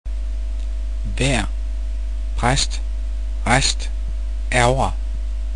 Danish Vowels
vejr [væ'a] (veather), præst [præsd], rest [ræsd] (rest), ærgre [æuræ] (annoy)